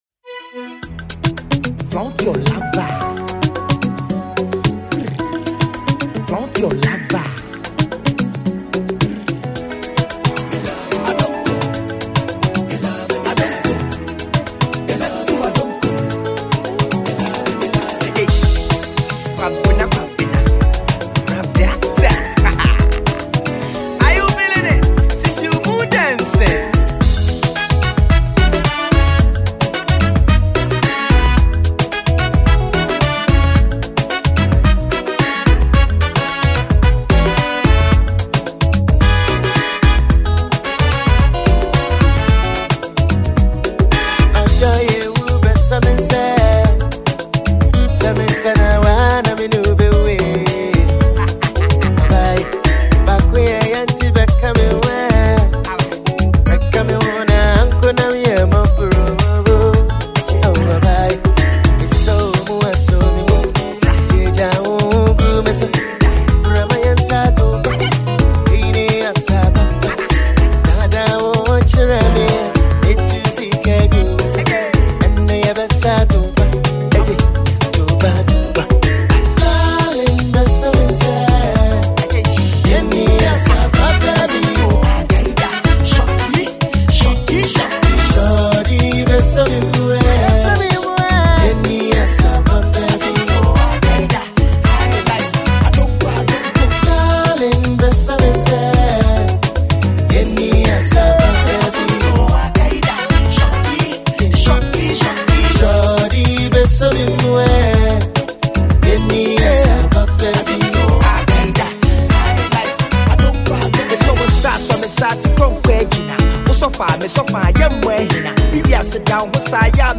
Hiplife